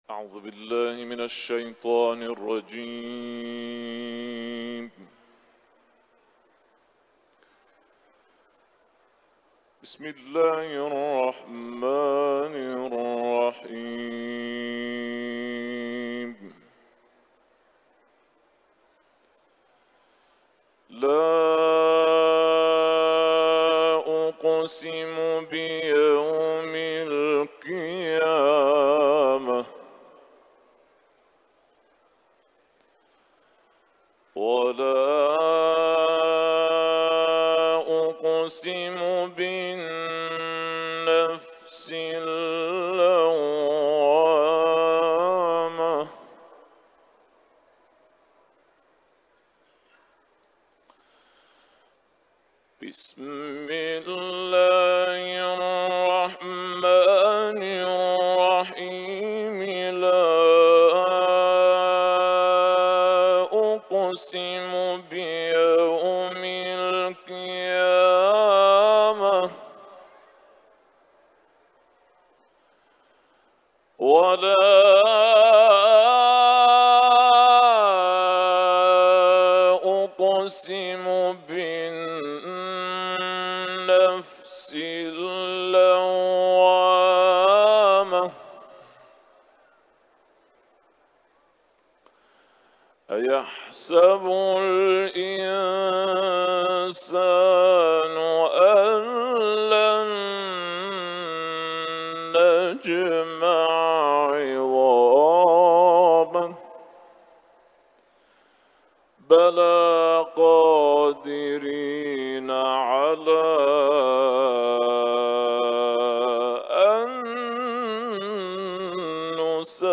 Etiketler: İranlı kâri ، kuran ، tilavet